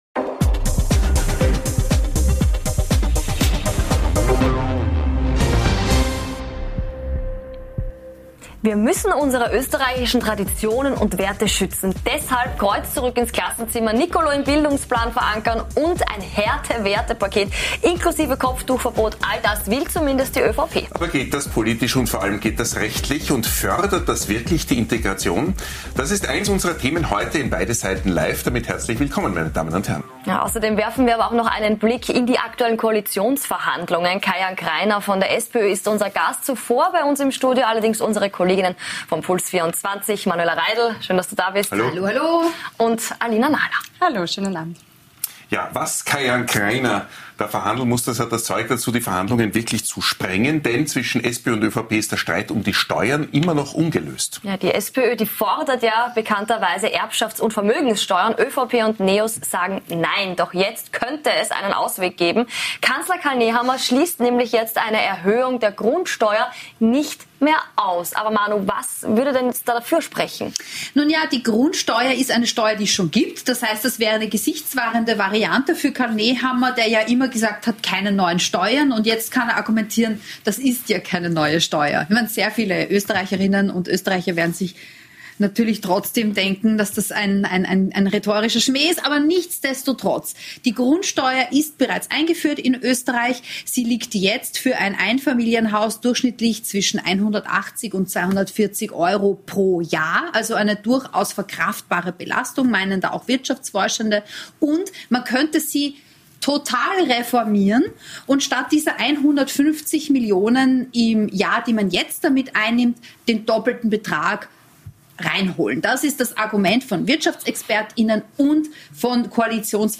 Beschreibung vor 1 Jahr Wir beleuchten beide Seiten zu folgenden Themen: Budgetkrise: Ist es sinnvoll die Grundsteuer zu adaptieren? "Härte Werte" - Wie die ÖVP den politischen Islam bekämpfen will Und nachgefragt haben wir heute bei gleich drei Gästen - Den Auftakt mach der SPÖ-Verhandler und Budgetexperte Kai Jan Krainer im Einzelinterview.